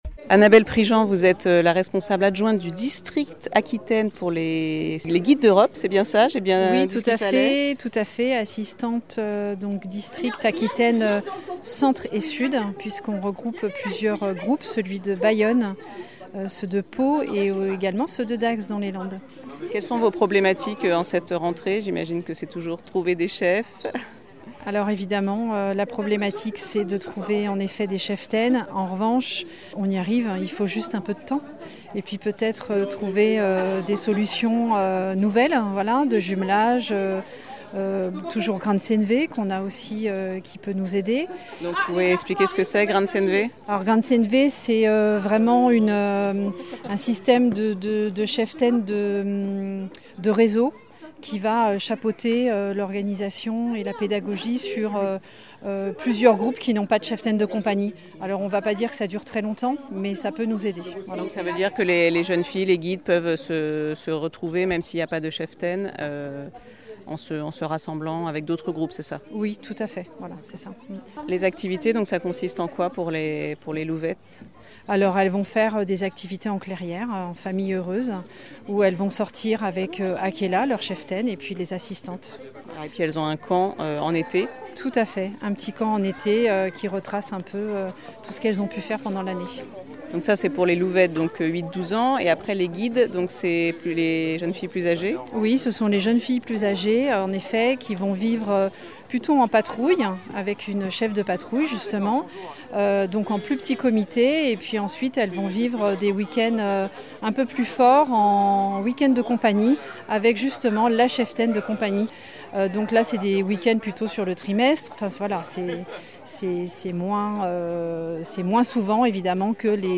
Interviews réalisés lors de la journée de rentrée des Scouts et guides d'Europe de Bayonne ;